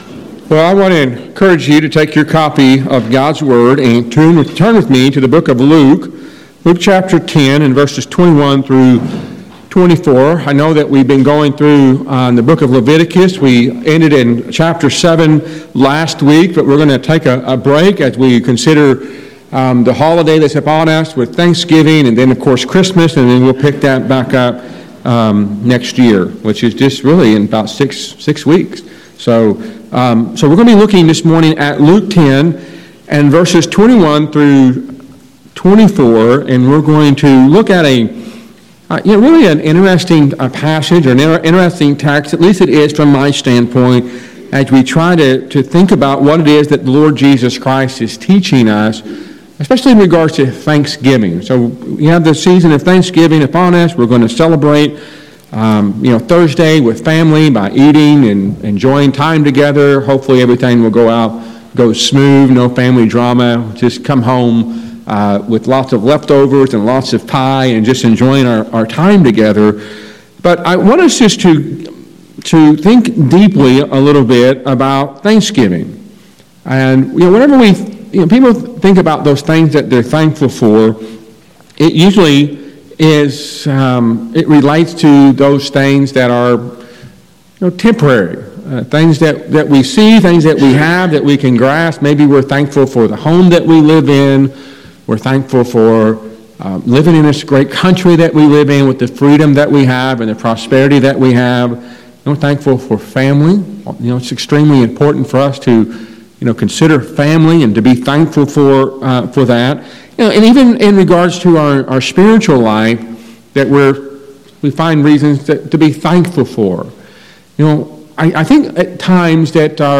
A sermon from Luke 10:21-24.